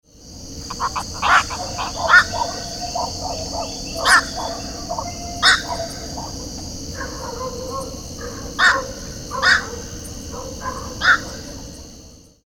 Nycticorax nycticorax
Quando vários deles se reúnem em busca da refeição fácil, geralmente junto com garças, as brigas são inevitáveis e podemos ouvir seus gritos bizarros.
Aprecie o canto do
Socó-dorminhoco